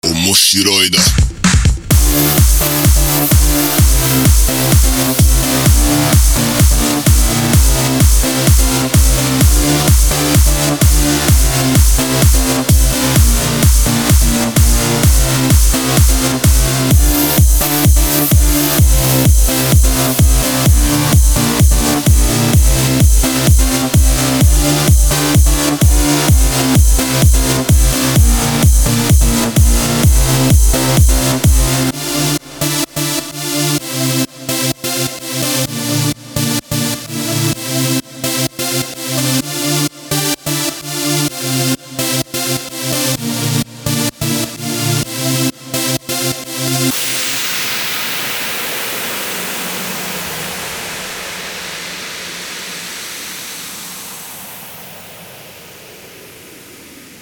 Рингтоны electro house
Клубные